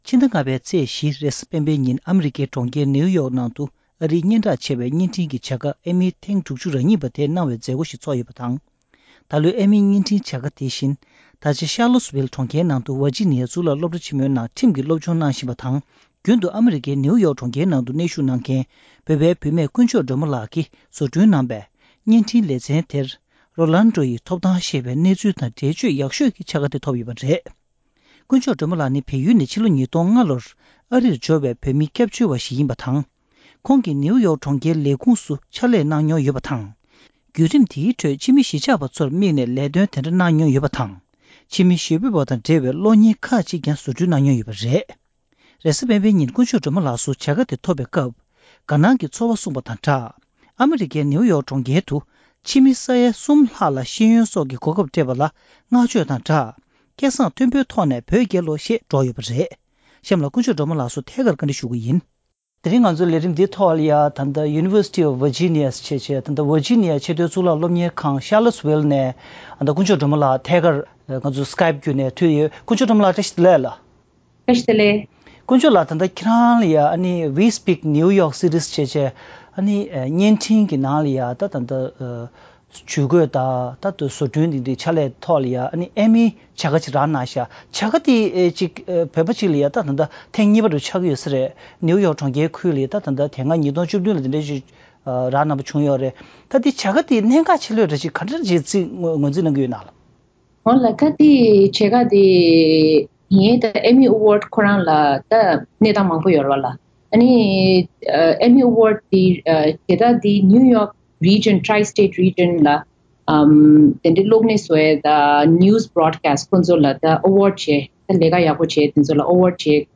བཀའ་དྲི་ཞུས་པའི་ལས་རིམ་ཞིག